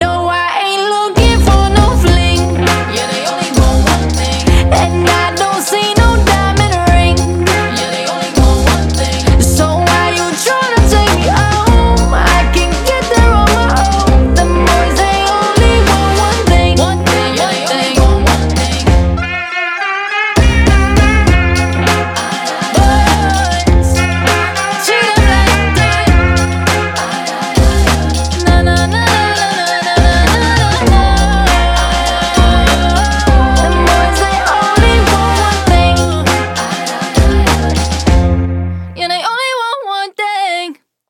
• Качество: 320, Stereo
поп
ритмичные
женский вокал